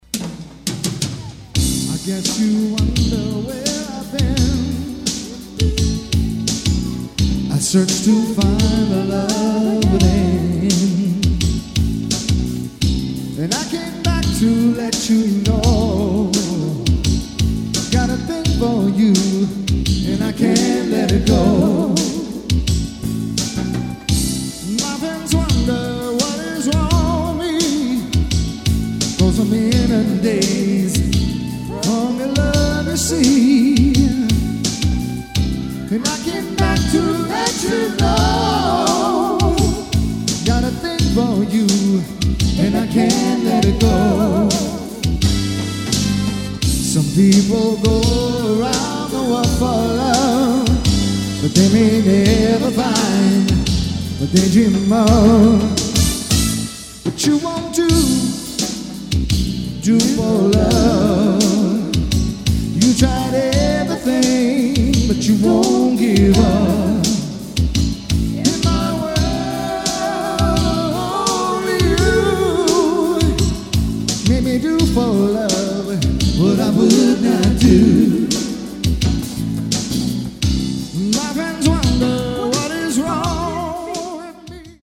and singing great R&B classics.